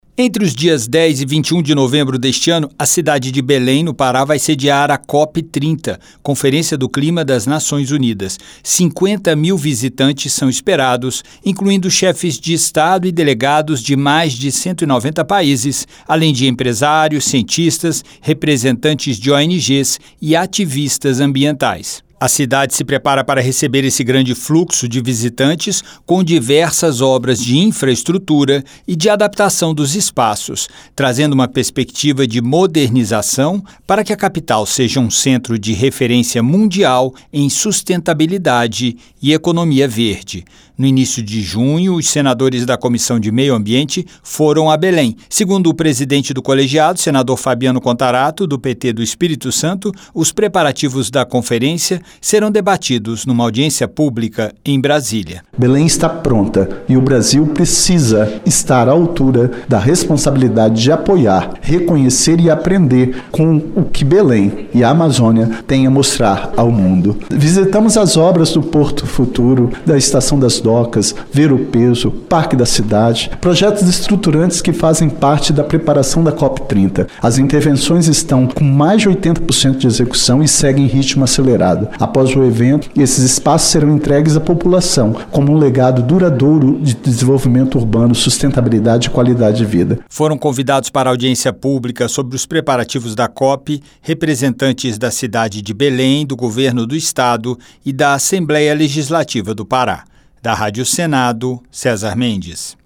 Autor do pedido do debate, o senador Fabiano Contarato (PT-ES) destacou que mais de 80% das obras estão prontas.